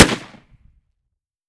fps_project_1/30-30 Lever Action Rifle - Gunshot A 005.wav at a5c5bcdb2a527f344e1efd25dad9a02ea0b389e1